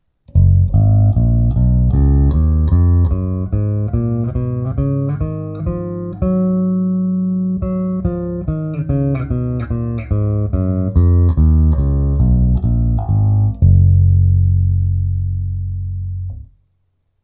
Všechny nahrávky jsou bez dodatečných úprav. Neodstraňoval jsem šum, neupravoval ekvalizaci, jen jsem nahrávky znormalizoval.